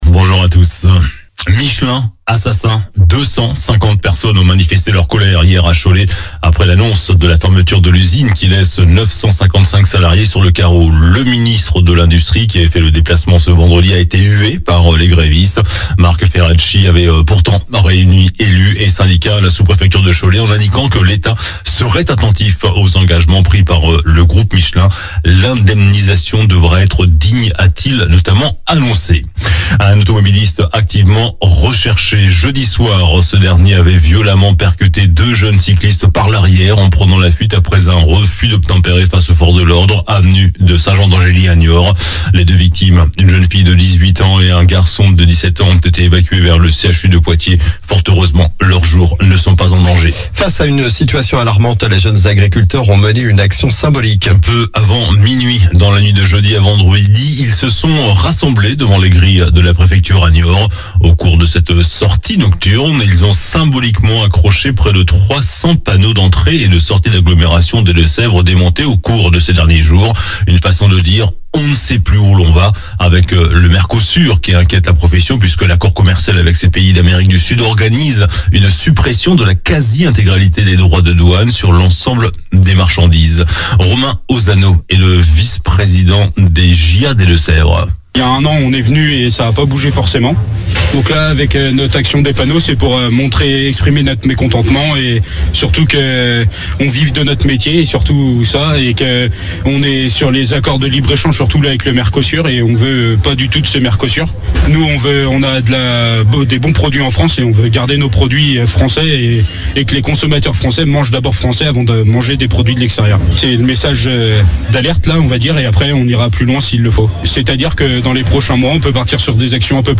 Journal du samedi 9 novembre